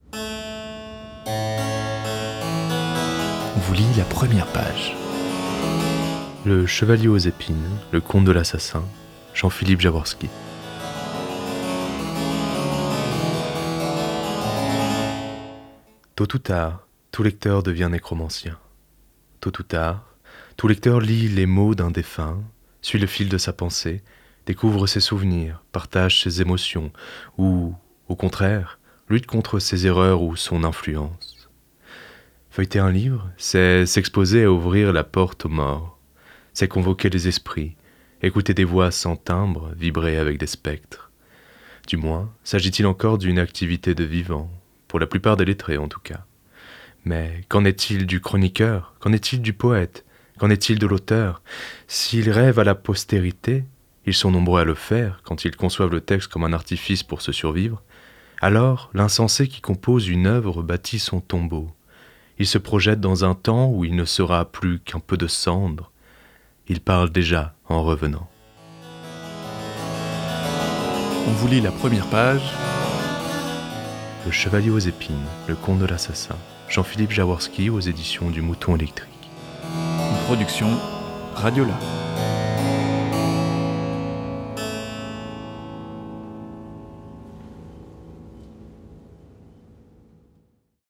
Dans l’émission Première page, RadioLà vous propose la lecture de l’incipit d’un roman.